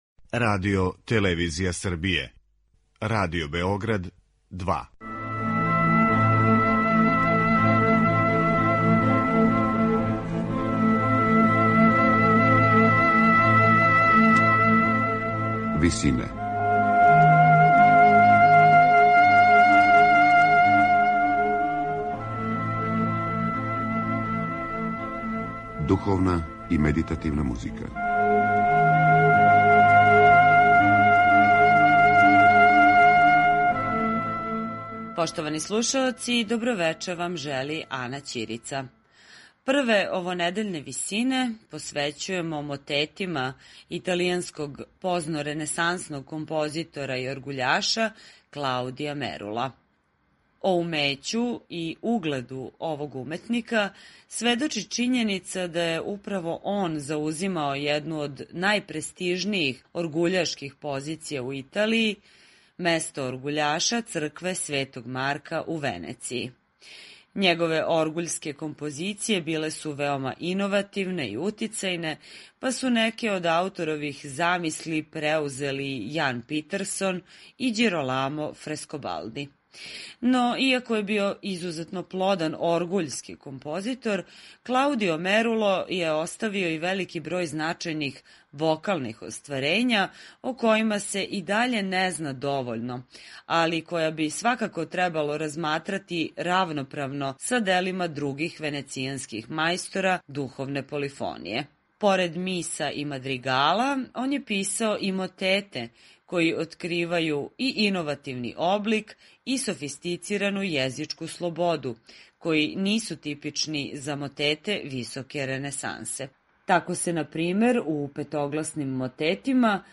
Мотети